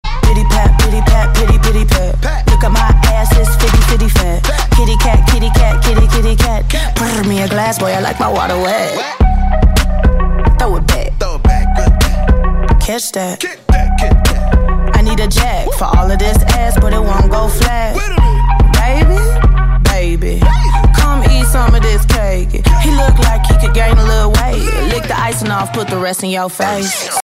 Ringtones Category: Rap - Hip Hop